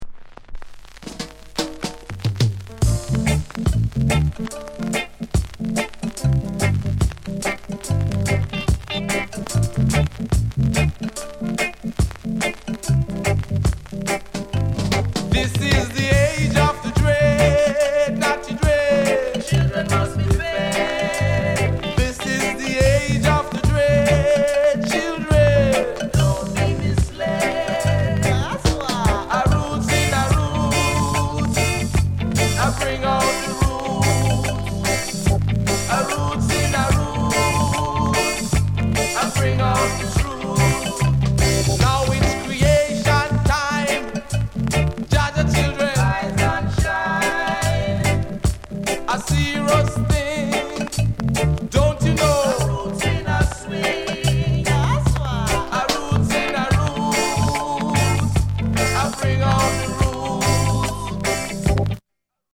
VERY RARE ROOTS